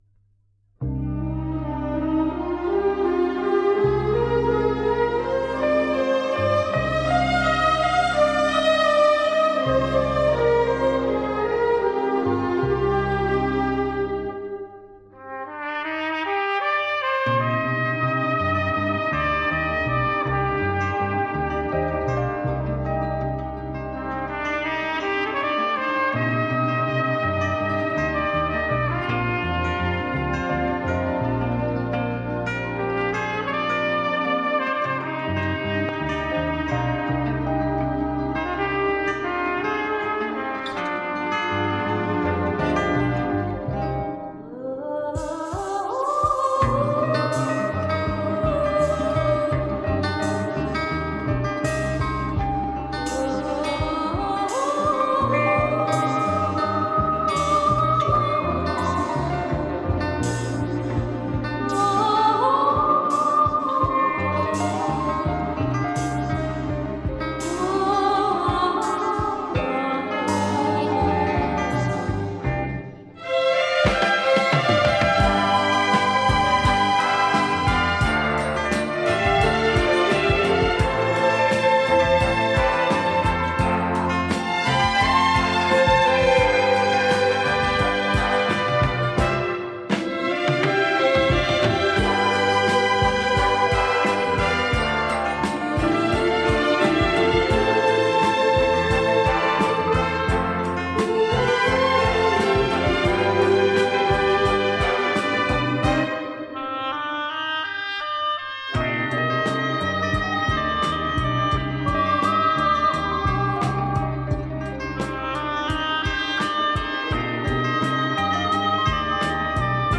Просто красивая и нежная мелодия